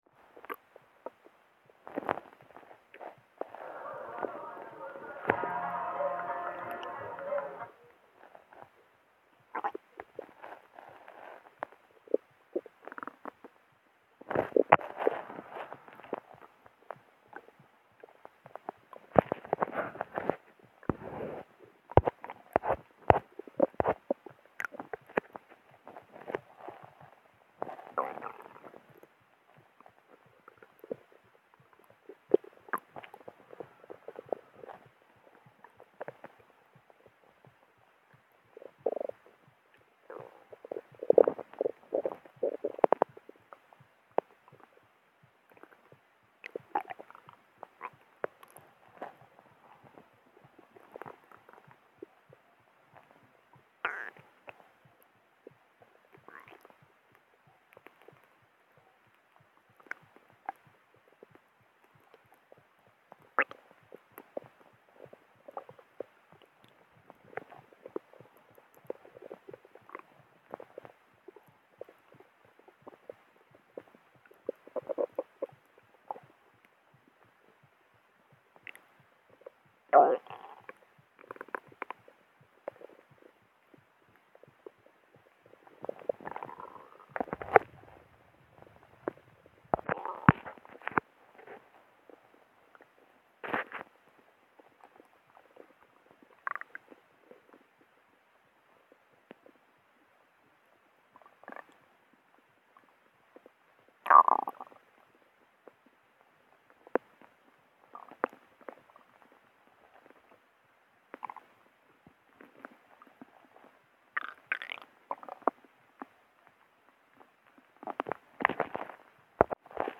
Gurgly stomach
It's my first external stomach audio
digestion